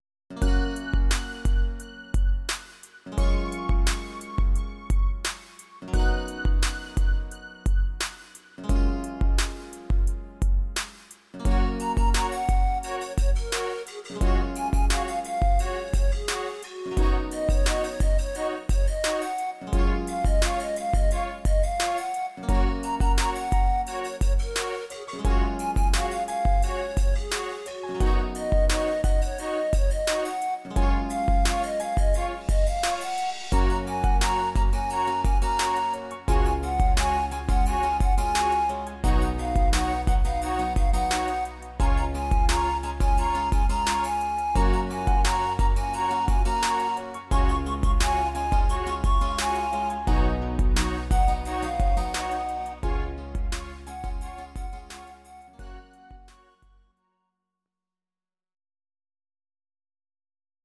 Audio Recordings based on Midi-files
Pop, 2010s